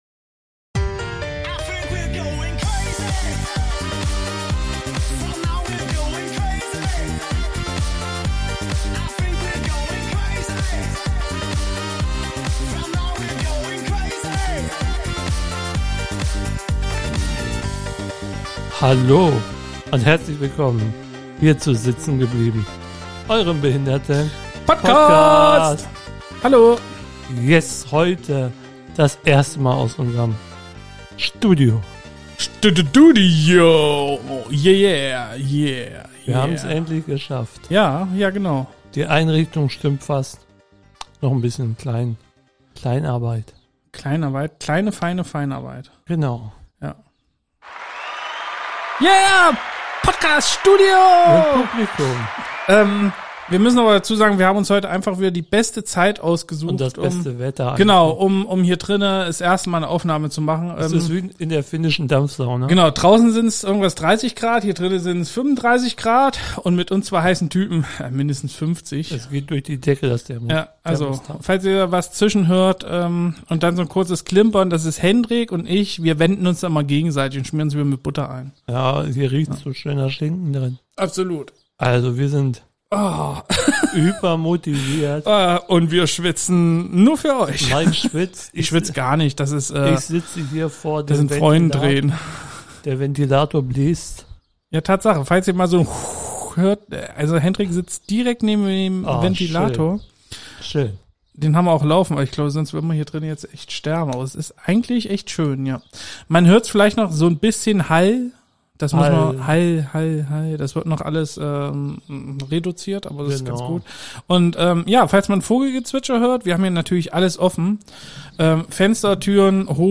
Beschreibung vor 3 Jahren Wir starten bei glühender Hitze mit der ersten Folge aus unserem Studio und genießen erst mal die neu gewonnene Freiheit. Technisch müssen wir noch einiges einstellen, weshalb ihr auch frischen Ventilatorensound auf die Ohren bekommt. Von jetzt an haben wir aber einen wunderschönen Ort um unsere Folgen aufzunehmen und auch wieder problemlos Gäste zu empfangen.